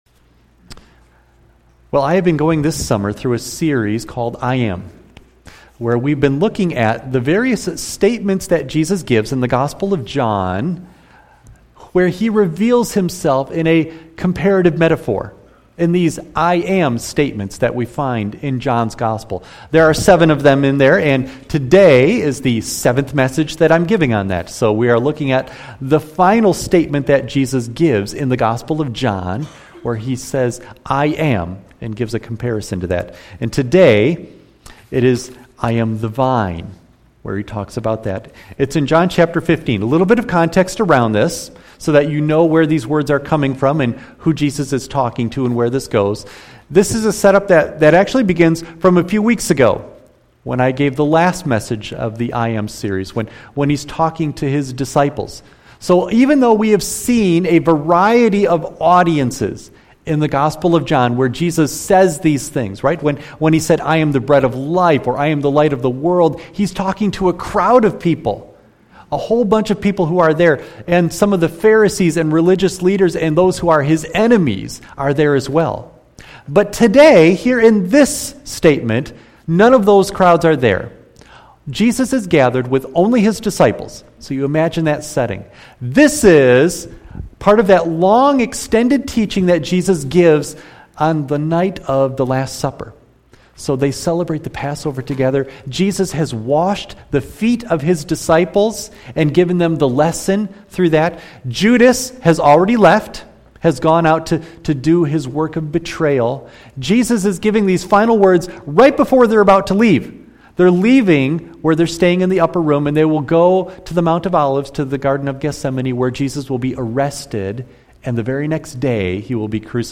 John 15:1-8 Service Type: Sunday AM Bible Text